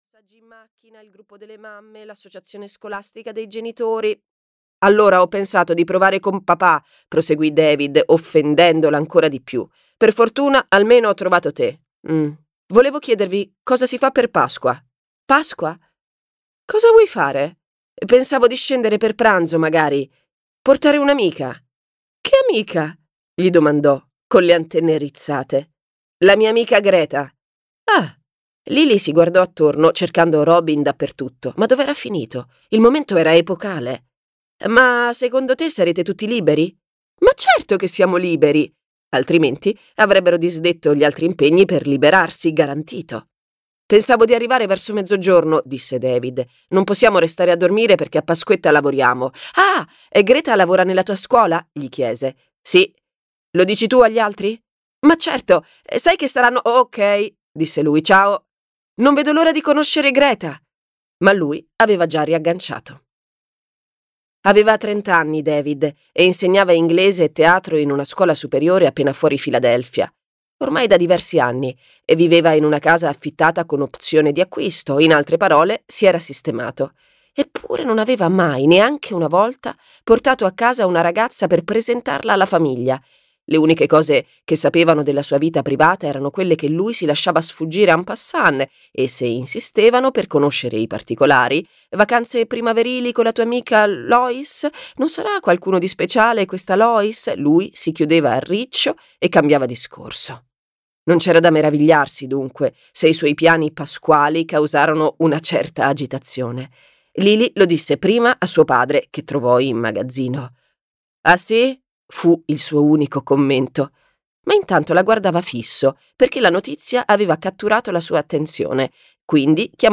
"La treccia alla francese" di Anne Tyler - Audiolibro digitale - AUDIOLIBRI LIQUIDI - Il Libraio